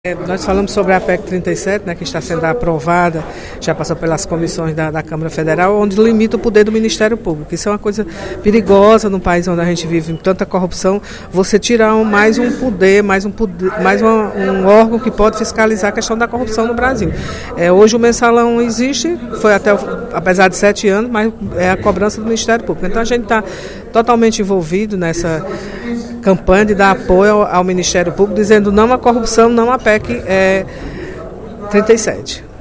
A deputada Mirian Sobreira (PSB) fez um apelo, durante o primeiro expediente da sessão plenária nesta terça-feira (12/03), para que os deputados se posicionem contrários à aprovação da proposta de emenda constitucional (PEC) 37, que retira o poder de investigação do Ministério Público.